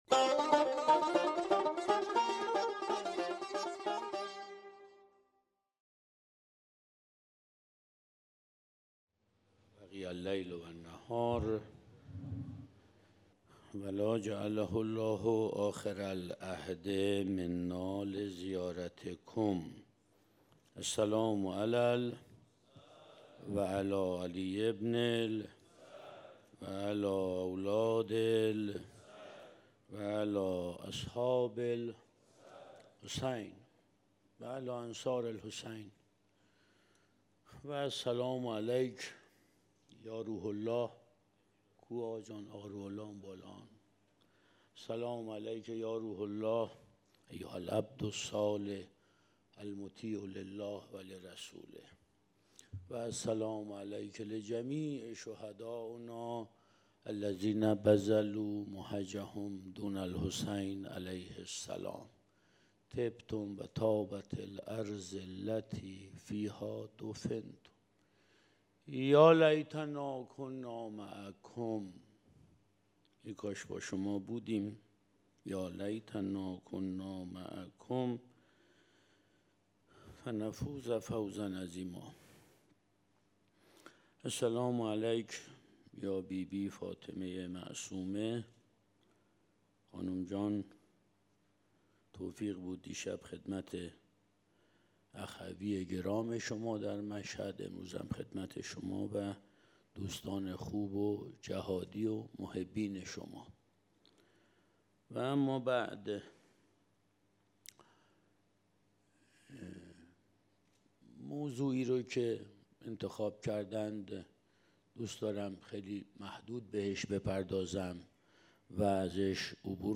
سخنرانی | مجاهد مهاجر
سخنرانی سردار سعید قاسمی | دومین اجلاس رابطان جامعه ایمانی مشعر | چهارشنبه 13 تیرماه 1397 - شهر مقدس قم، مجتمع یاوران مهدی (عج)